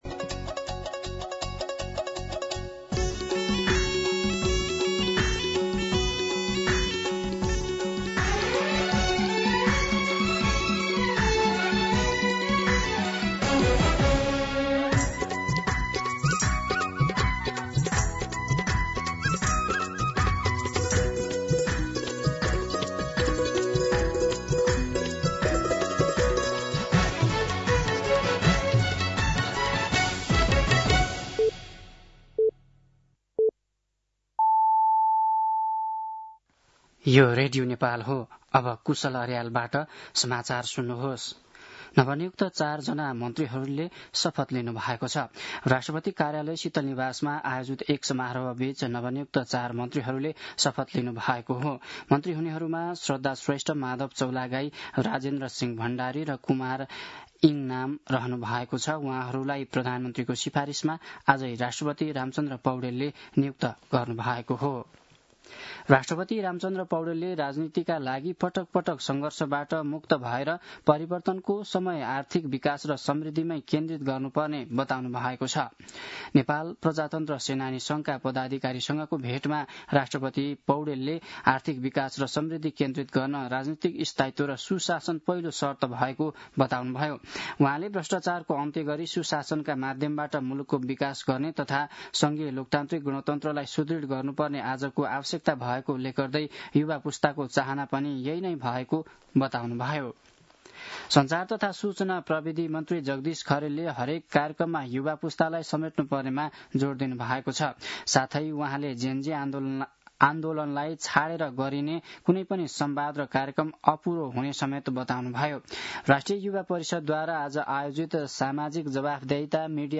दिउँसो ४ बजेको नेपाली समाचार : २६ मंसिर , २०८२